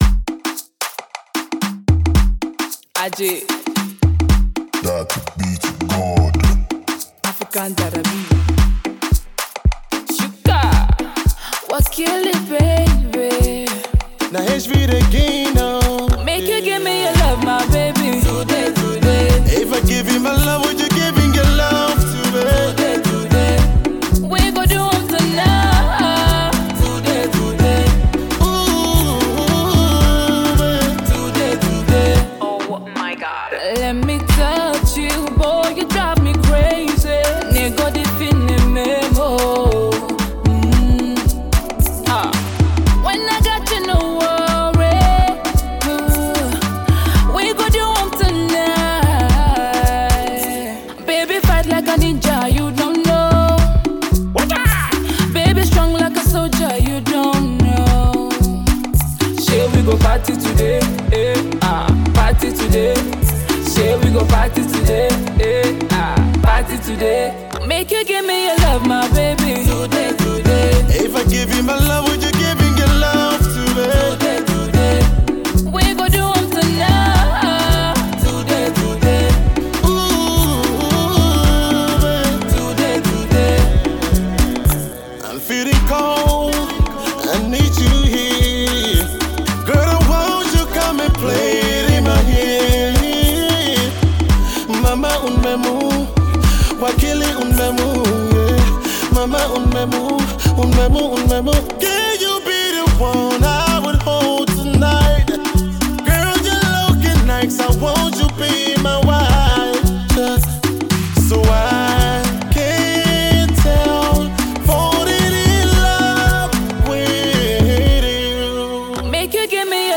Afropop
RnB/ Afropop